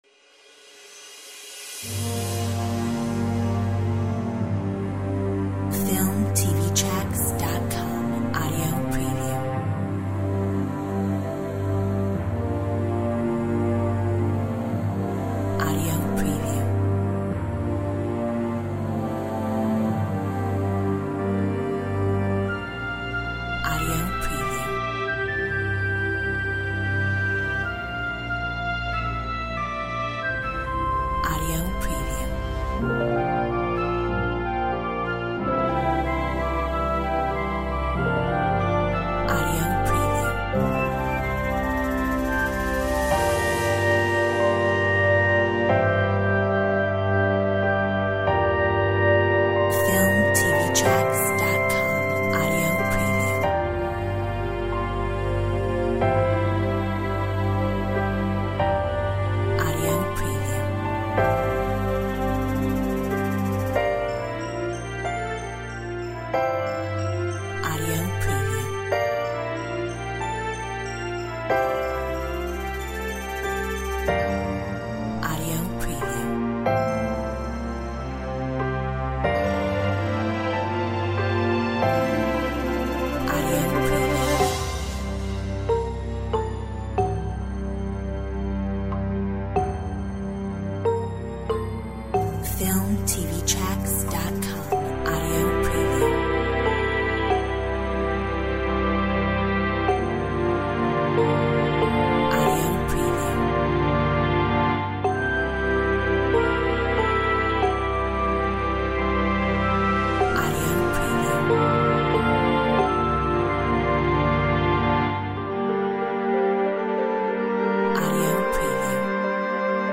Royalty Free Epic Orchestral Music